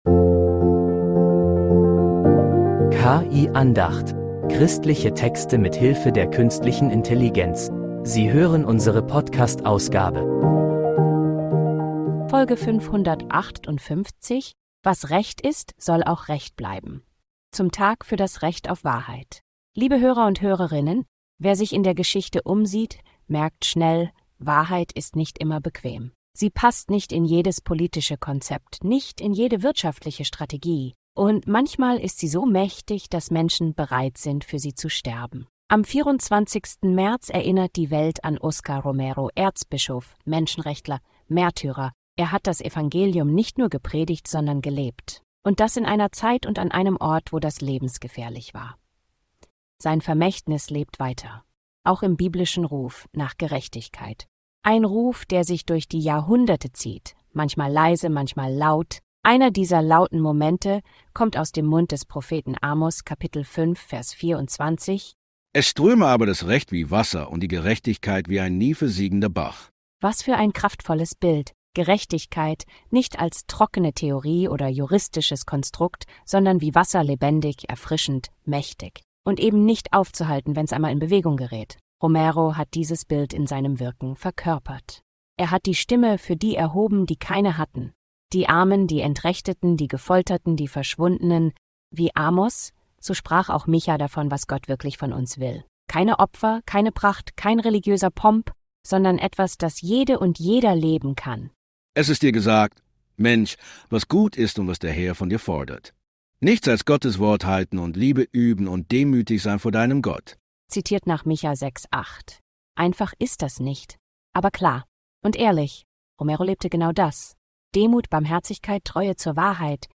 sie trotzdem ausgesprochen werden muss, das zeigt diese Andacht.